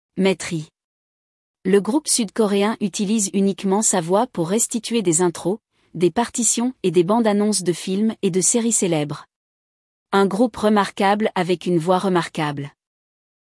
acapella
Een opmerkelijke groep met opmerkelijke stem.